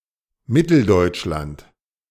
Central Germany (German: Mitteldeutschland [ˈmɪtl̩ˌdɔʏtʃlant]